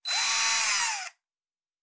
pgs/Assets/Audio/Sci-Fi Sounds/Mechanical/Servo Small 2_3.wav at master
Servo Small 2_3.wav